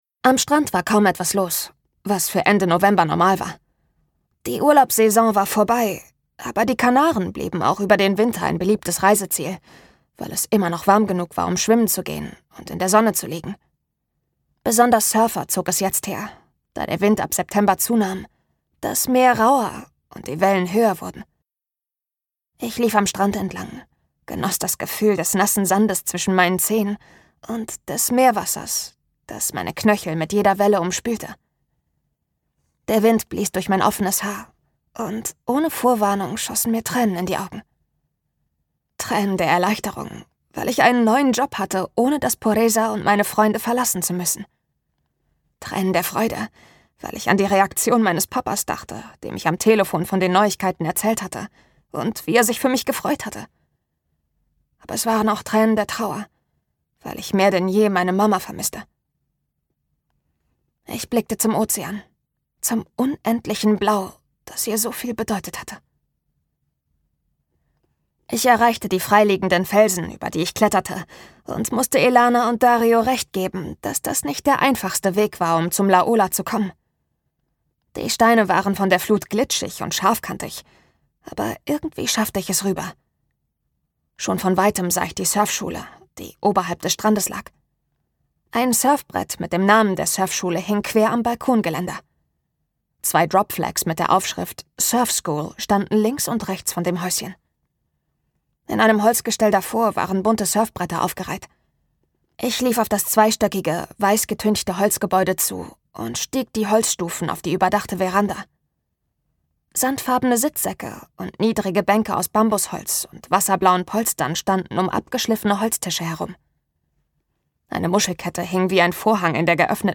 Gekürzt Autorisierte, d.h. von Autor:innen und / oder Verlagen freigegebene, bearbeitete Fassung.
Hörbücher mit verwandten Themen